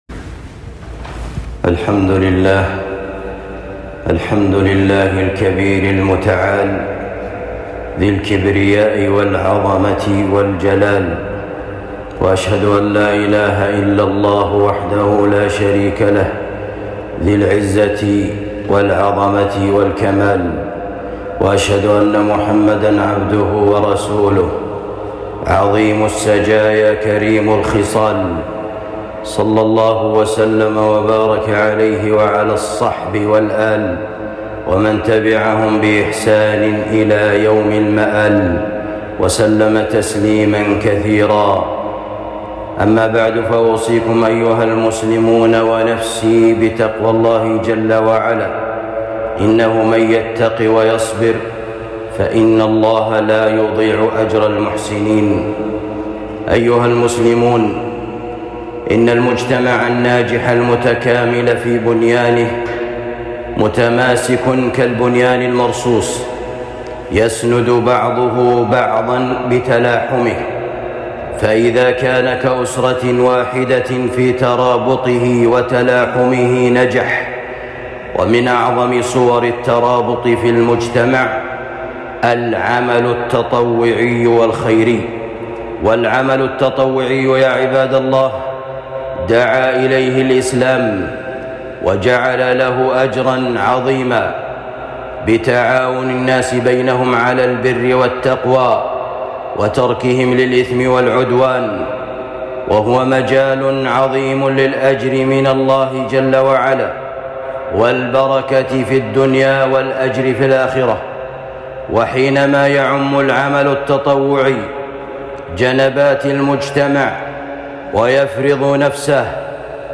خطبة جمعة بعنوان العمل التطوعي أبعاده وآثاره